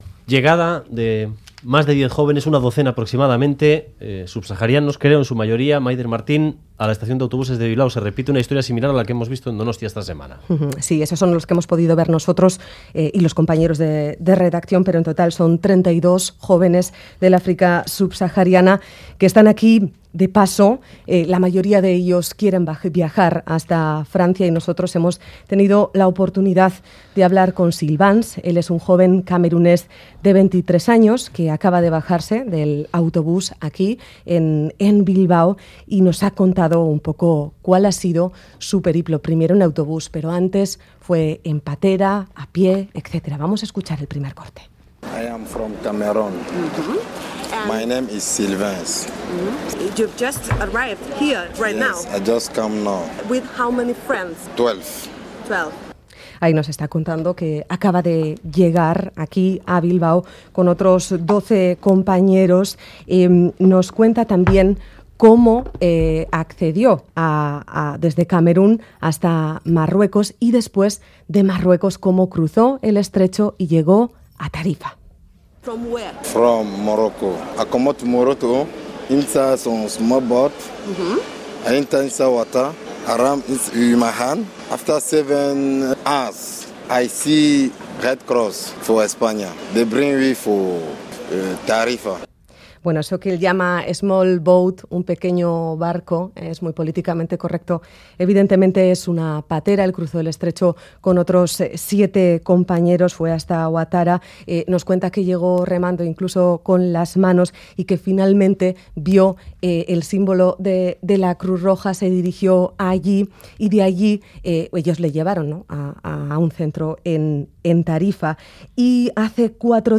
Audio: Tras la llegada a Donostia de un autobús con 46 migrantes provenientes del África Subsahariana, hoy recalaba en Bilbao otro con 12 más. Hemos hablado con uno de ellos